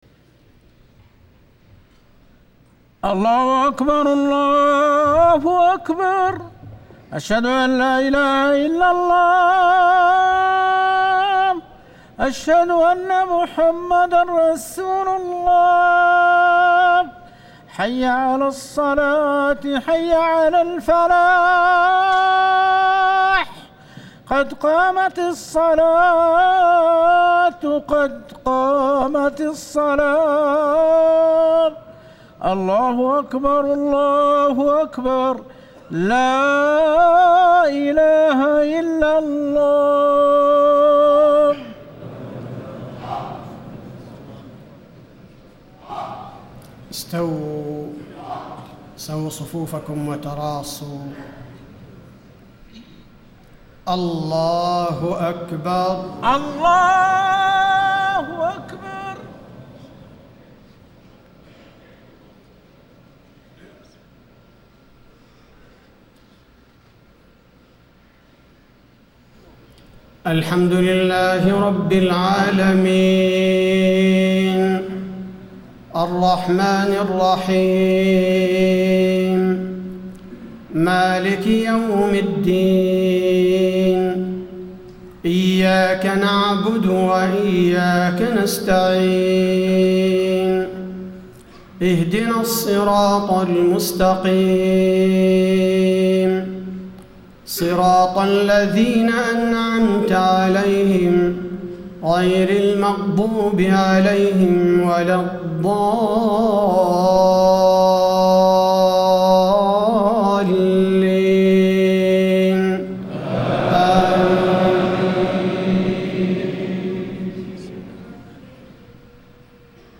صلاة الجمعة 6-5-1435 من سورتي يس و المنافقون > 1435 🕌 > الفروض - تلاوات الحرمين